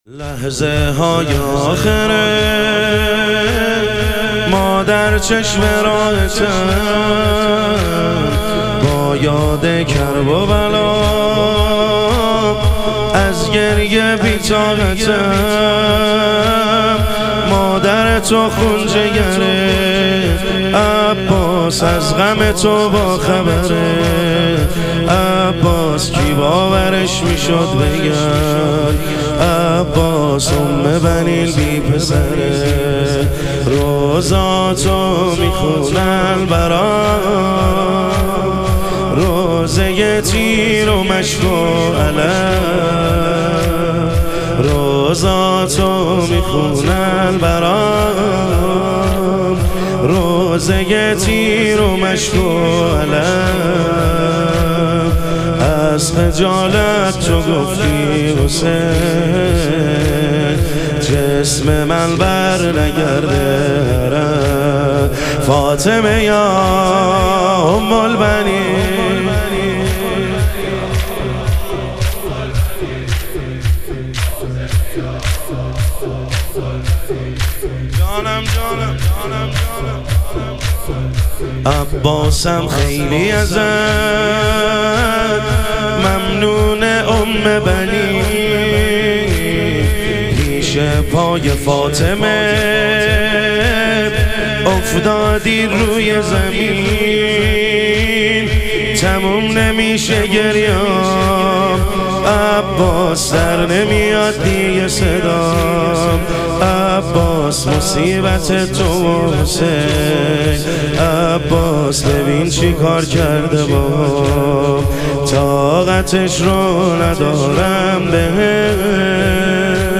شهادت حضرت ام البنین علیها سلام - تک